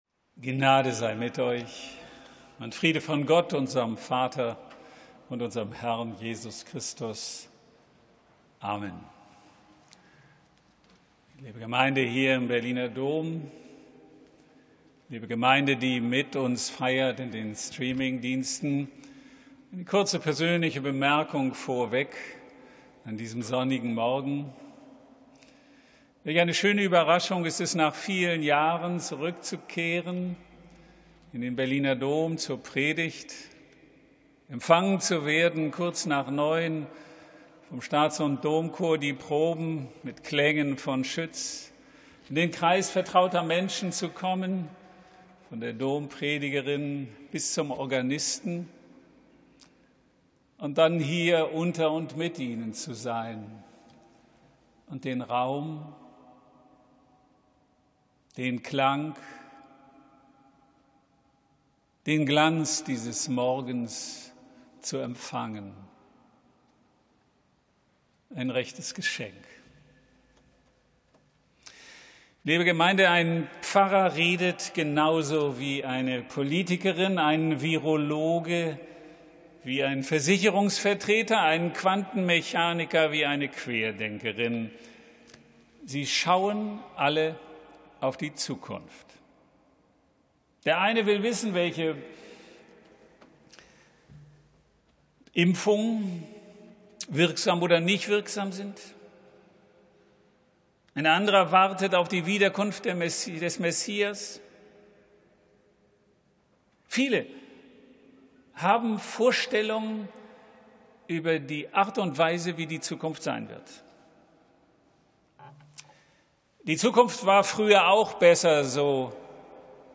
Die Oberpfarr- und Domkirche zu Berlin in der Mitte der Stadt.
Sermons 2022